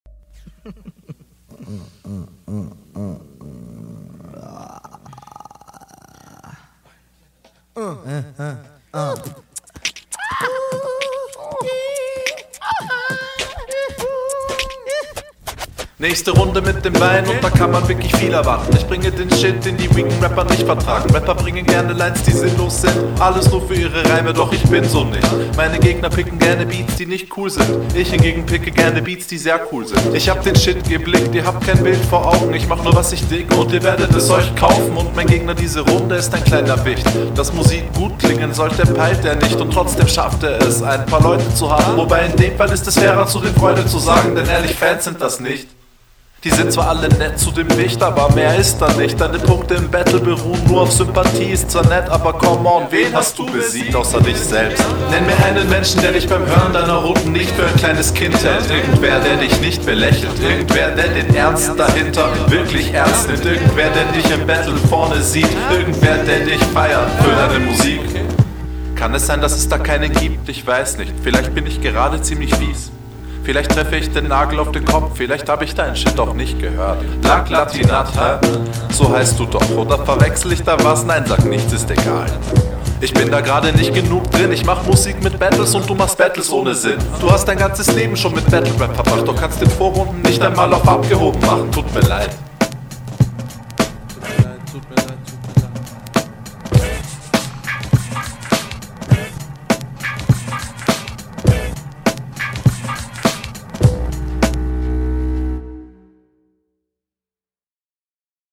Mega geiler Beat macht super laune. Reverb stört mich etwas leider.